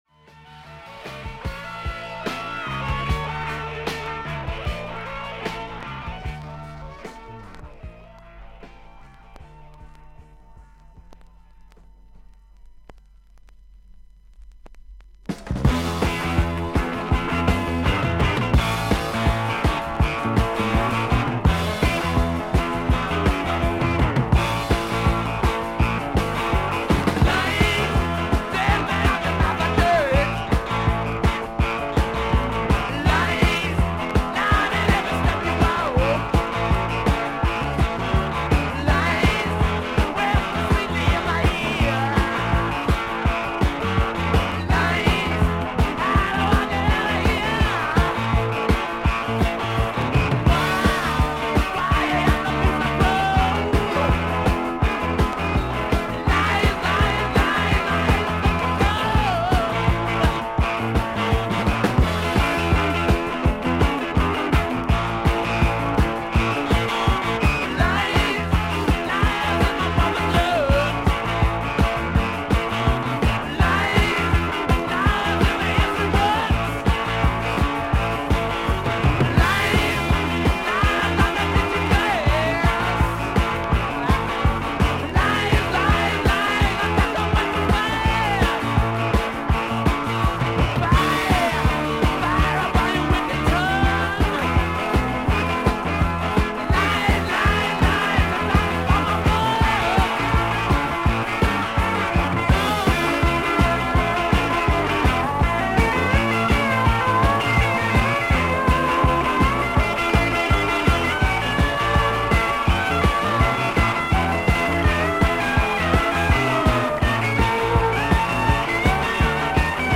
イギリス盤 / 12インチ LP レコード / ステレオ盤
少々軽い周回ノイズあり。
ほかはVG+〜VG++：少々軽いパチノイズの箇所あり。少々サーフィス・ノイズあり。クリアな音です。